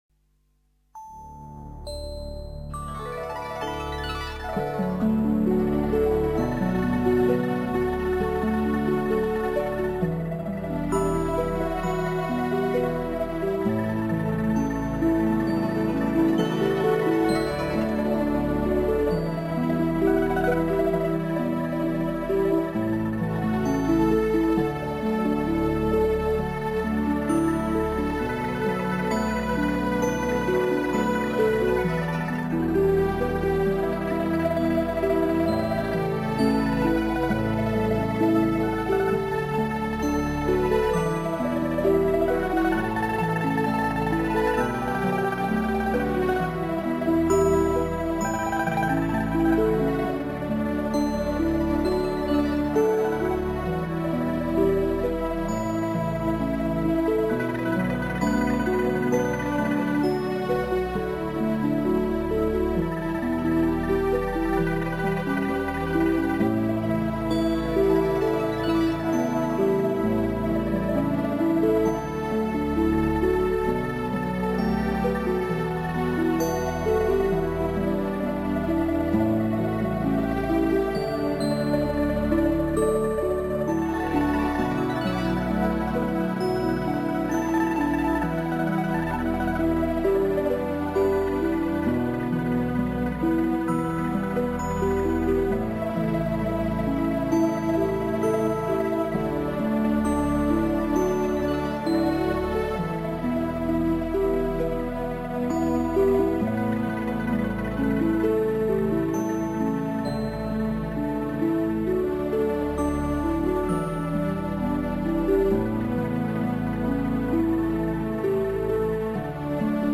142-平湖秋月伴奏.mp3